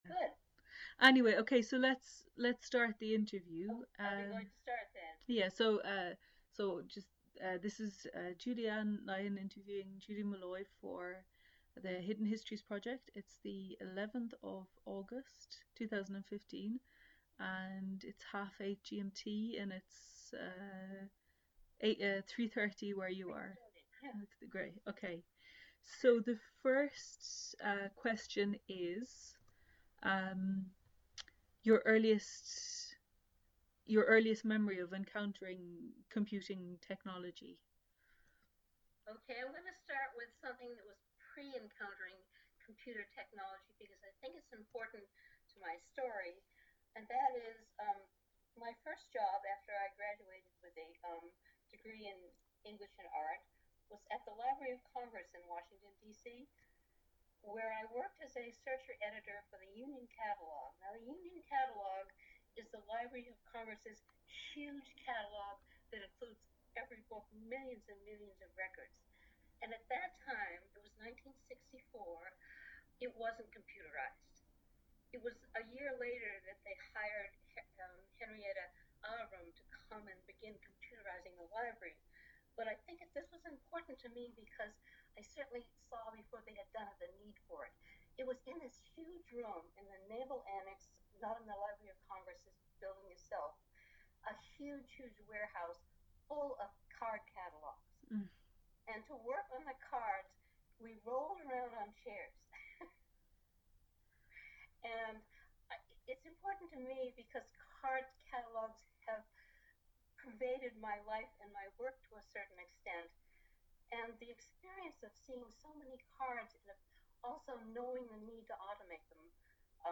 Location Skype interview
Subject An oral history interview for the Hidden Histories project Processed Derivative Material Fu